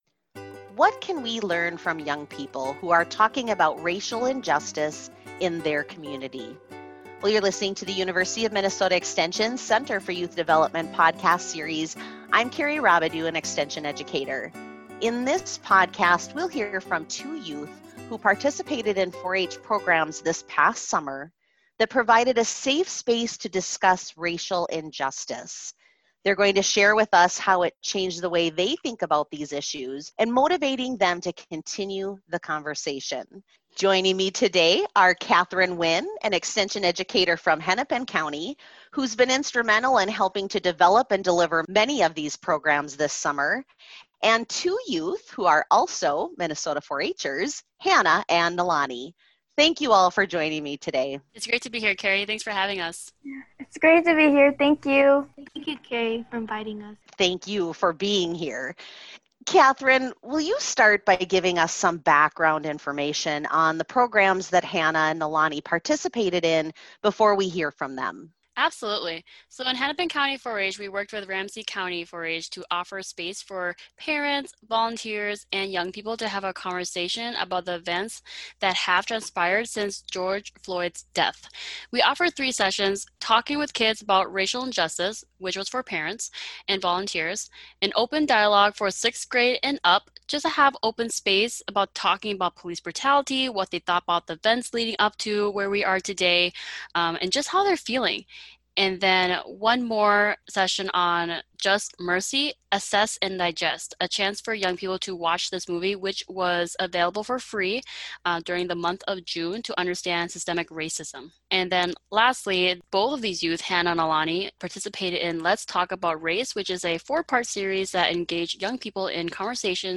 In this podcast, we will hear from two Hmong-American youth who participated in 4-H programs this past summer that provided a safe space to discuss racial injustice. They share how it changed the way they think about these issues and has motivated them to continue the conversation with their peers.